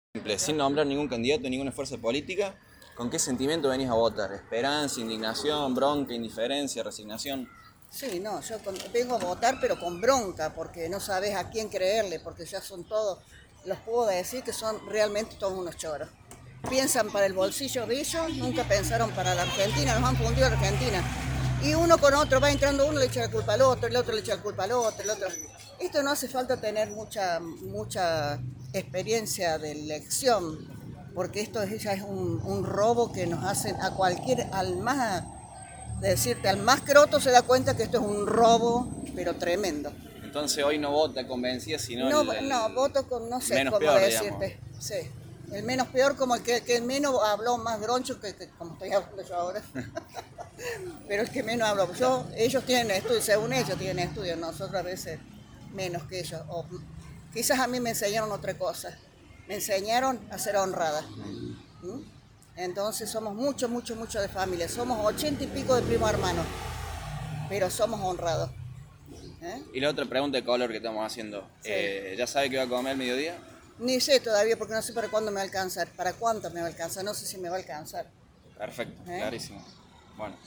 Entrevistas realizadas en los colegios Caminante, Rivadavia y Bianco.